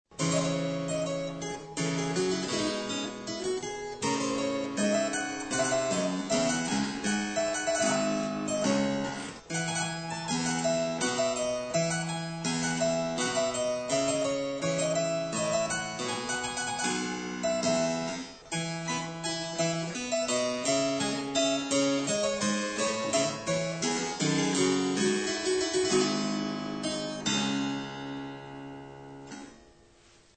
Epinette anglaise
Le son est plus nasillard que celui du clavecin.
Elle a un jeu de 8 pieds.